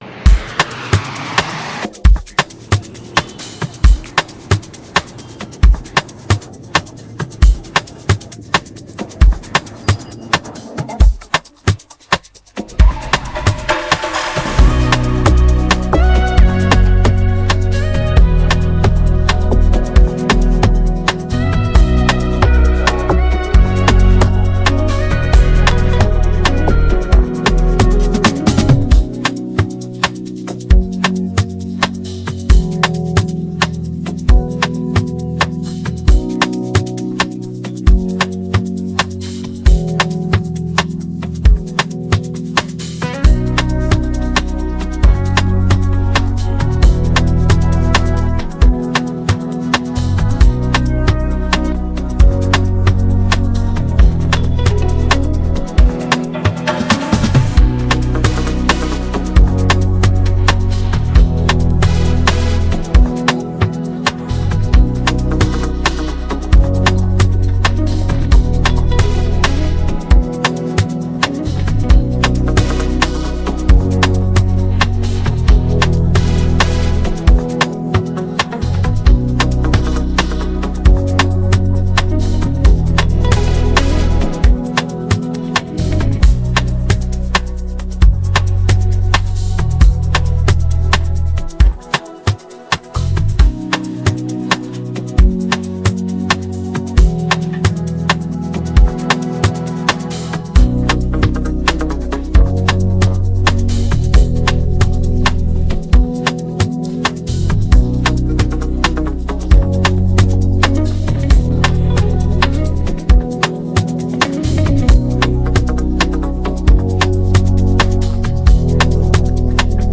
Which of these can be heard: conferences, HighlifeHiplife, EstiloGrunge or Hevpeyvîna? HighlifeHiplife